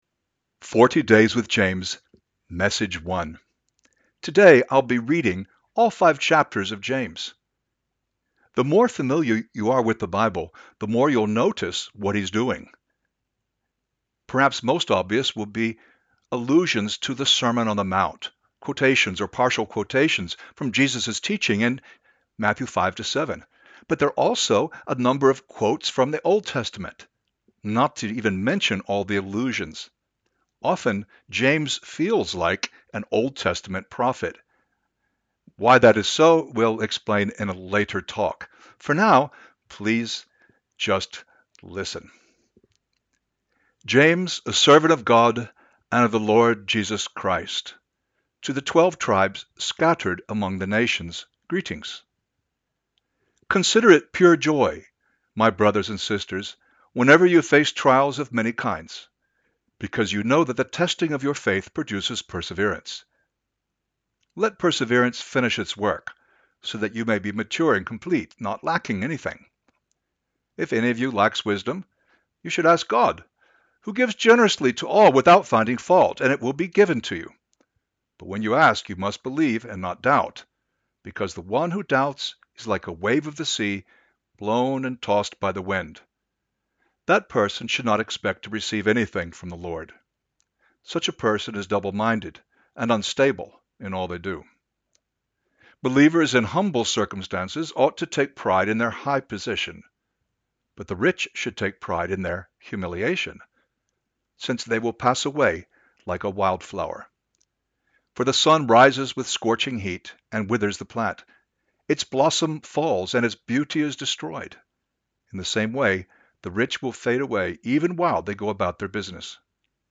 The audio recording is a reading of the entire book of James, in the NIV.